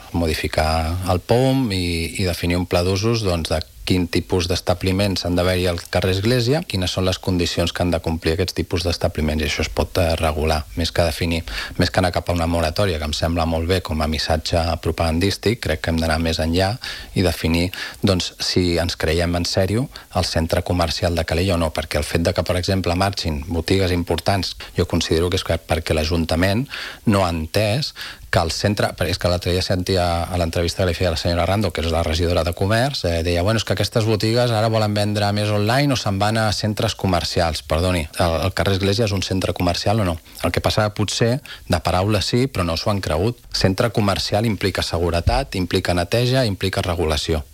Així de contundent s’ha mostrat Enric Gómez, regidor portaveu d’Estimem Calella, en una entrevista aquest dijous al matinal de RCT, on ha carregat contra la gestió del govern municipal en matèria de promoció de ciutat.